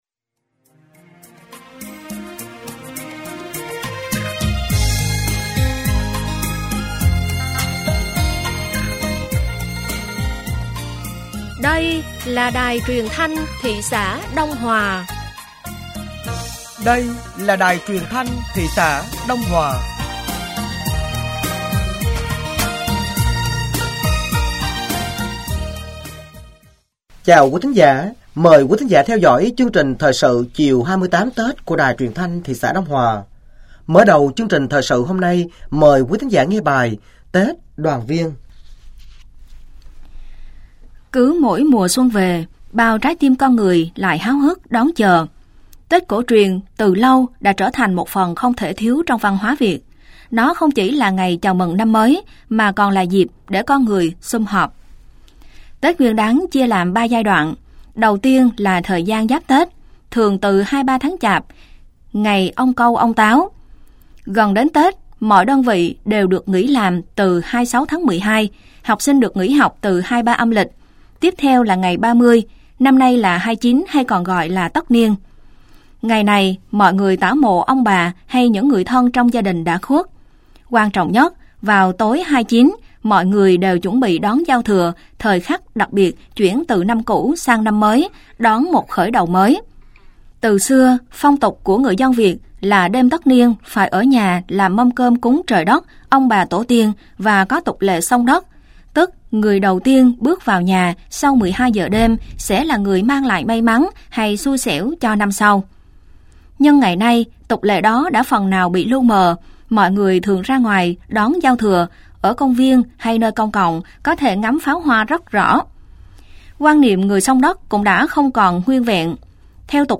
Thời sự tối 28 Tết - tháng Chạp